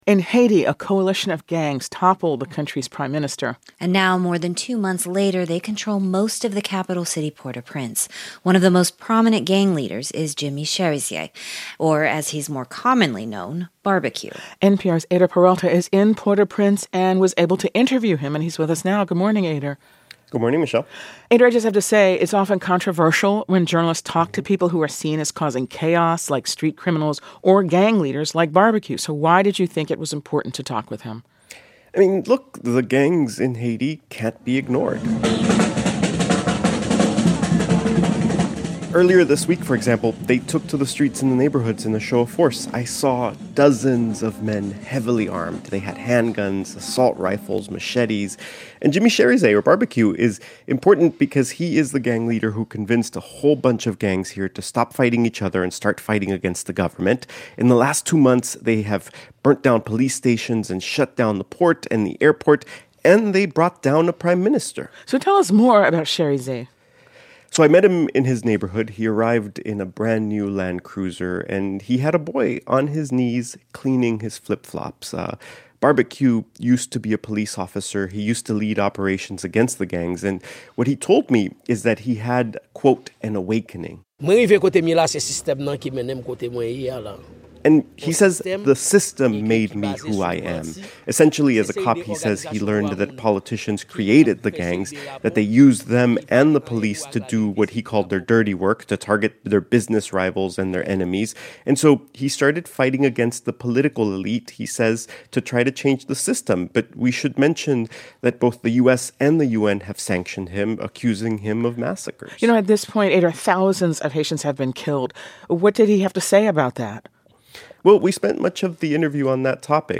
Barbecue is the man who convinced many of Haiti's gangs to stop fighting each other and start fighting the government. He spoke to NPR about his latest plans.